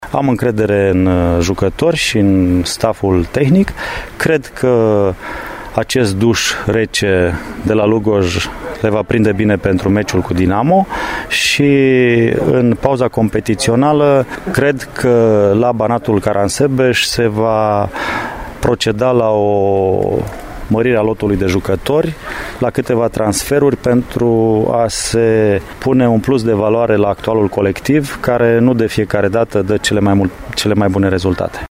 Vela consideră că înfrângerea, de la Lugoj, cu SCMU Craiova, este ca un duş rece înaintea partidei pe care Banatul Caransebeş o va disputa pe terenul echipei de pe locul secund, Dinamo Bucureşti şi anunţă noi transferuri în pauza de iarnă. Ascultaţi-l pe edilul gugulanilor în fişierul de mai jos.
marcel-vela-despre-situatia-de-la-banatul.mp3